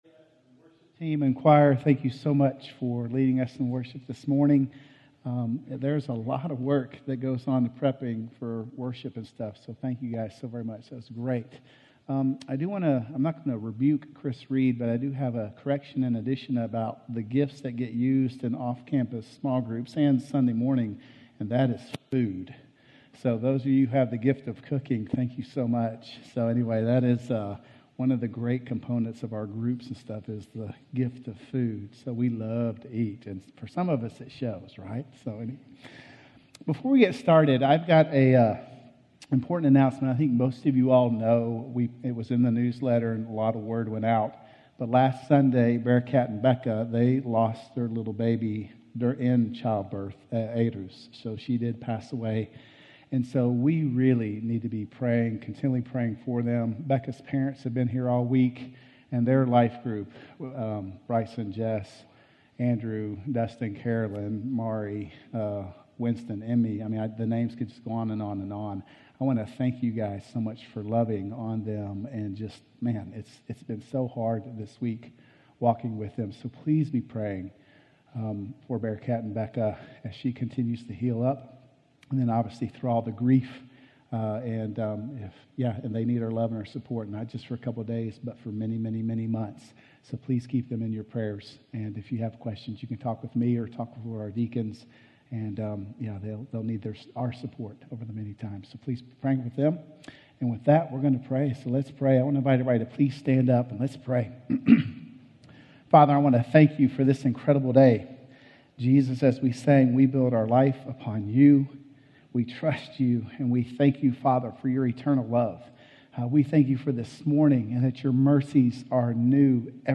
Dealing With Anxiety - Sermon - Woodbine